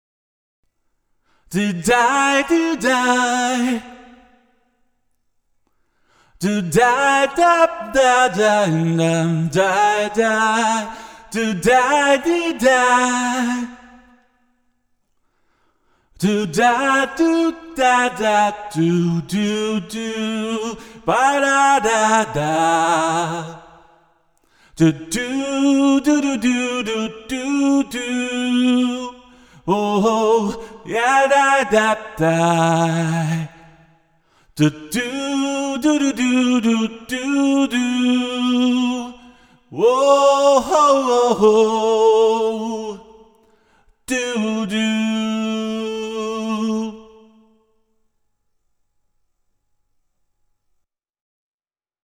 Parhaiten viiveen kuulee silloin, kun laittaa sekä suoran että prosessoidun raidan samanaikaisesti päälle. Tässä on suora laulu oikeassa ja Mic Mechanicilta äänitetty signaali vasemmassa kanavassa:
mic-mechanic-l-direct-r-e28093-no-piano.mp3